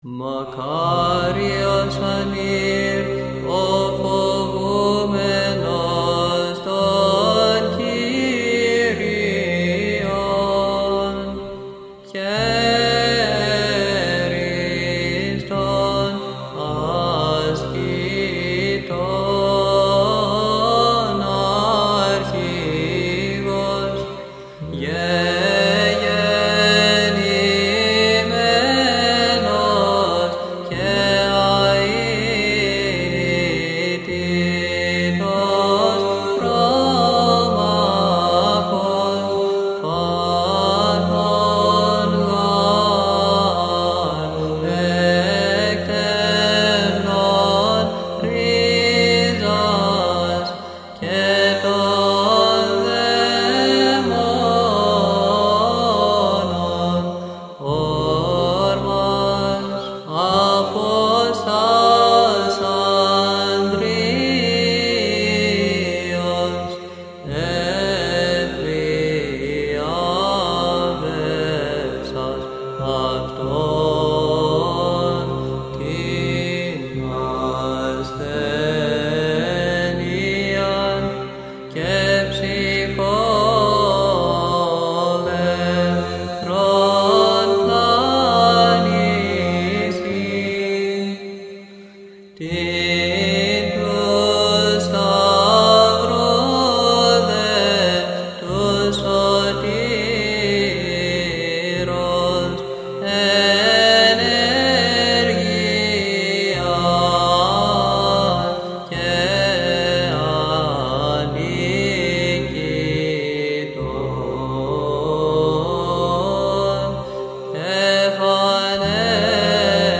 Index of: /public/Βυζαντινή/Ι.Μ. Αγίου Αντωνίου (Αμερική Αριζόνα)/Αγρυπνία/